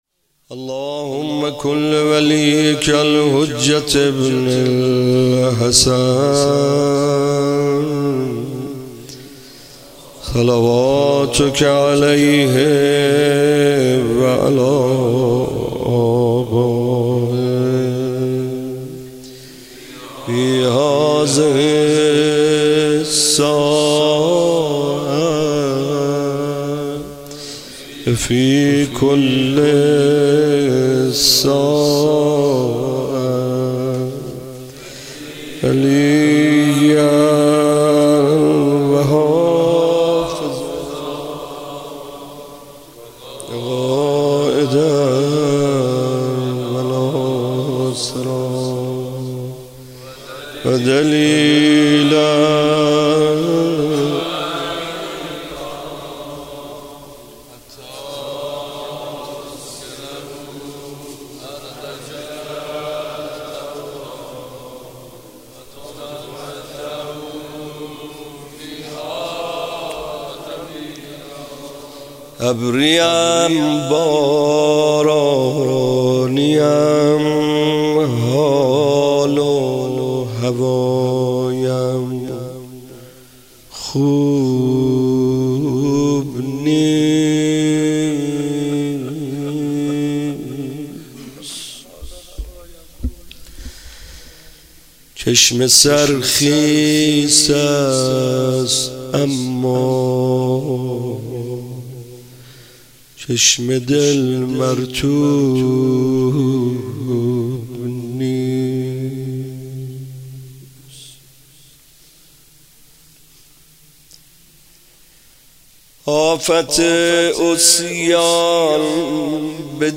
ماه رمضان 97